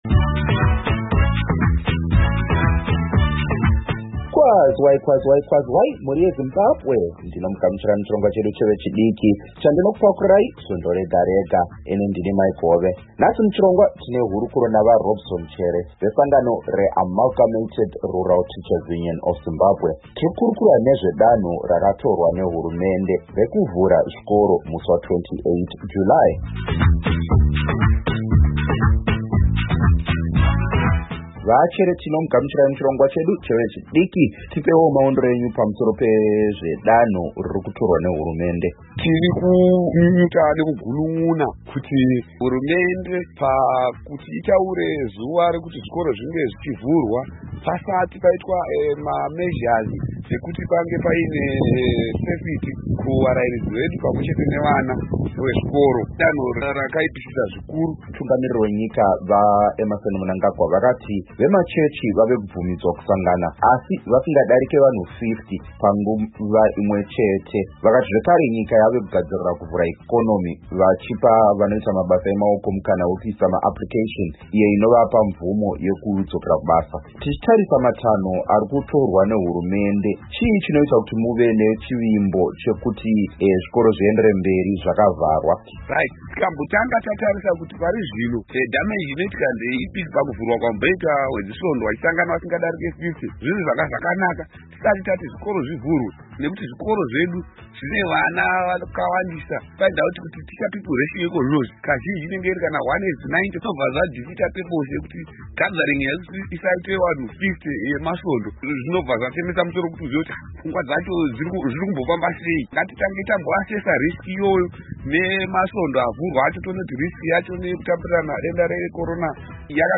Muchirongwa chedu chevechidiki kana kuti Youth Forum, tine hurukuro